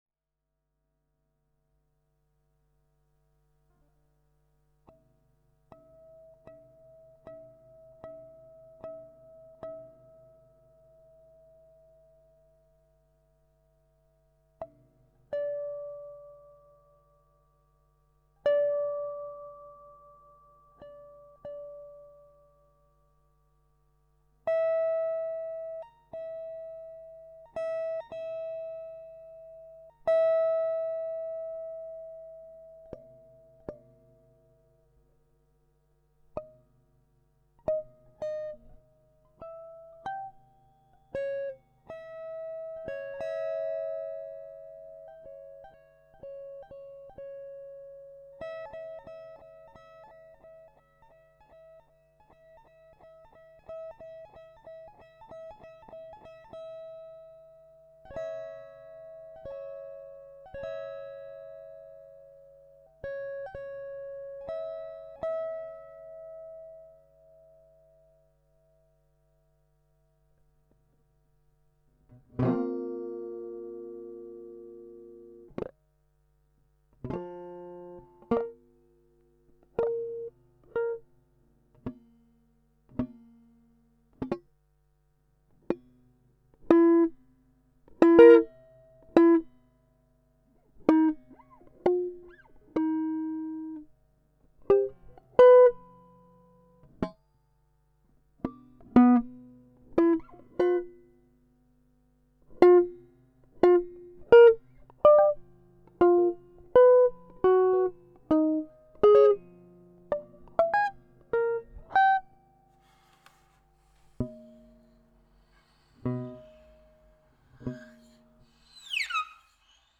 sound knife in the sand